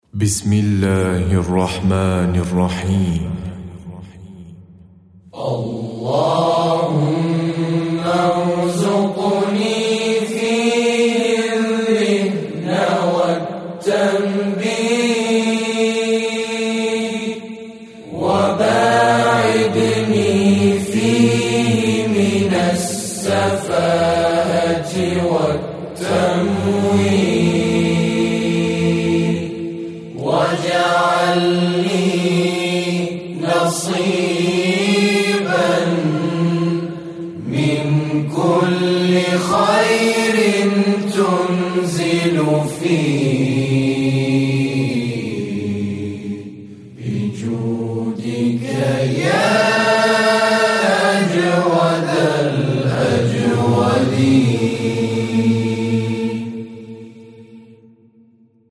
نماهنگ و سرود رسمی و معنوی